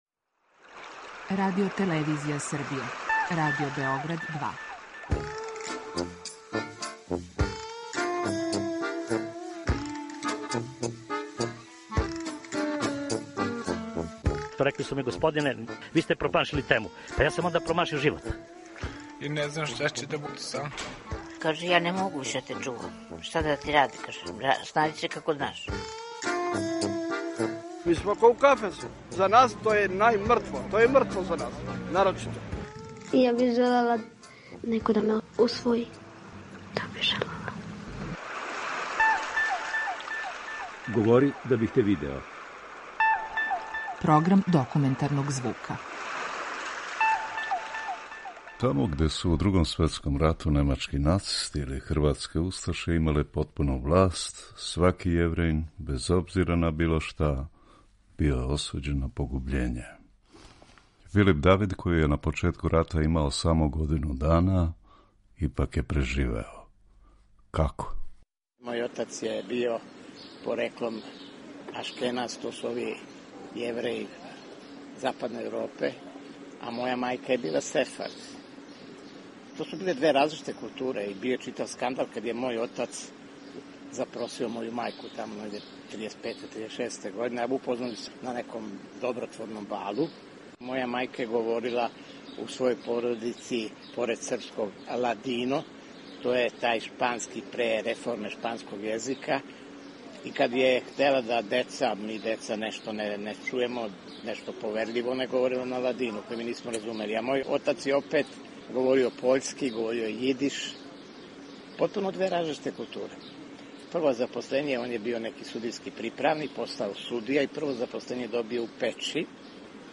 filip-radio-bg.mp3